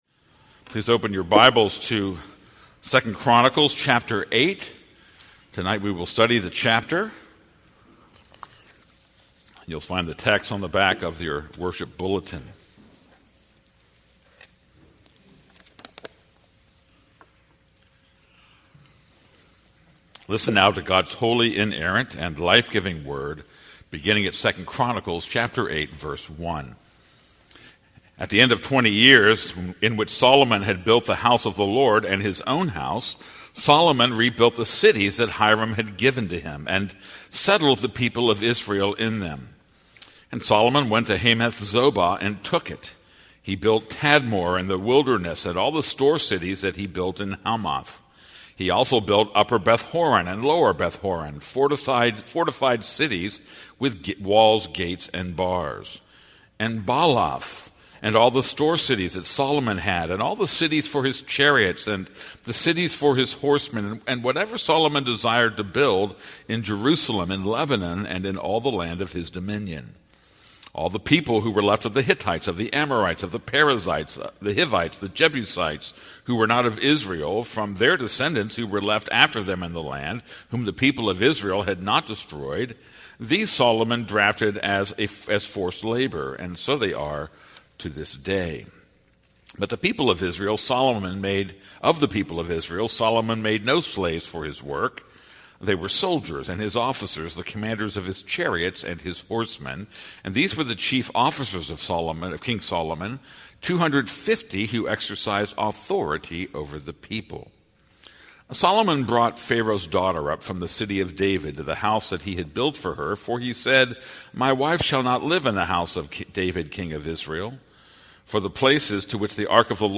This is a sermon on 2 Chronicles 8:1-18.